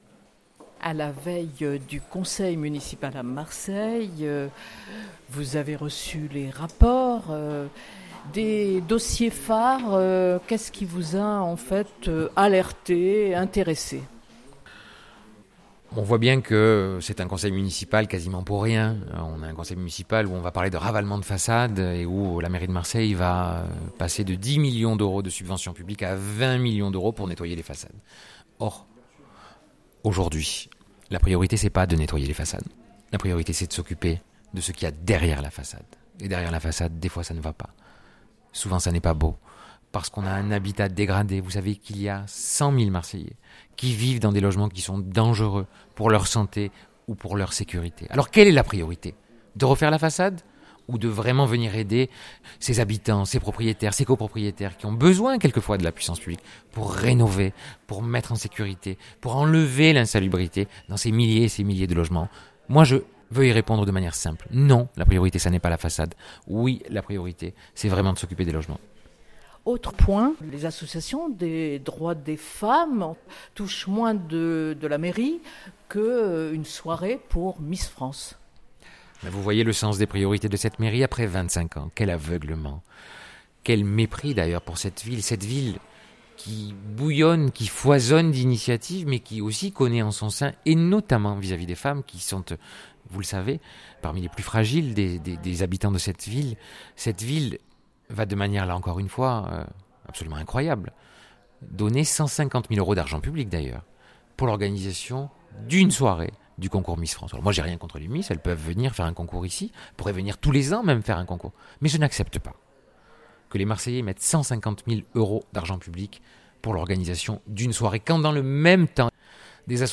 benoit_payan_avant_conseil_municipal_du_17_06_2019.mp3